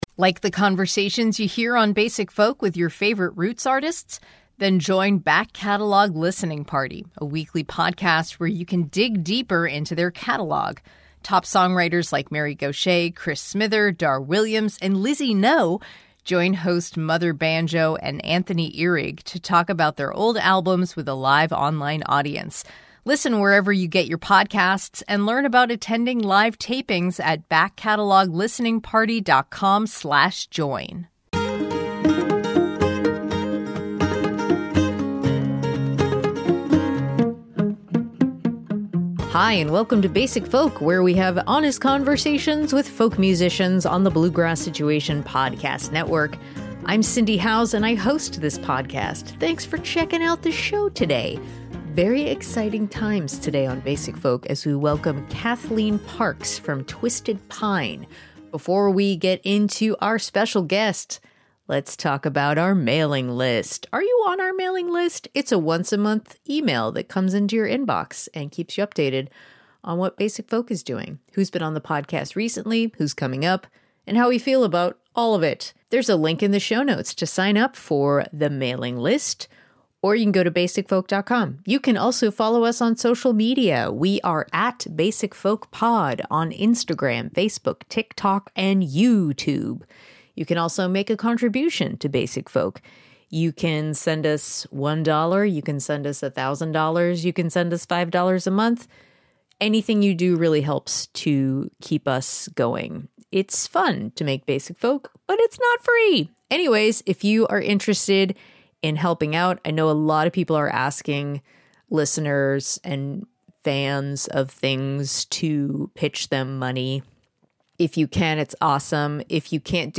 In our Basic Folk conversation, we explore the mental health themes highlighted in “Funky People,” a song about how difficult it can be to take care of yourself on the road and the relief you find in people you meet.